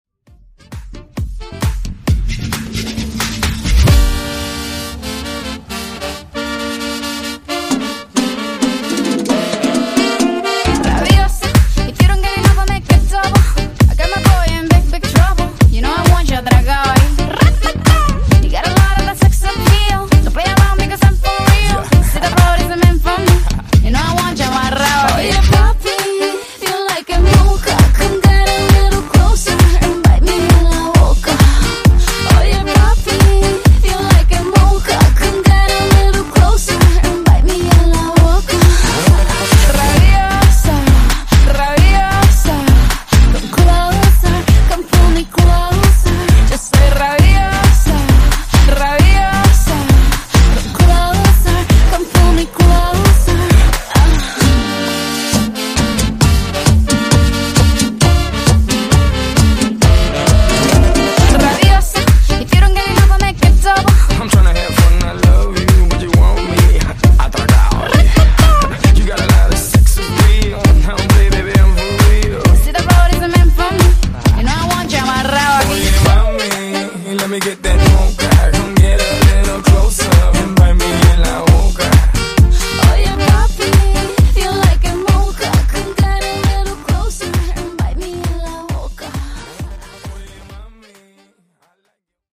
Genre: RE-DRUM
Clean BPM: 123 Time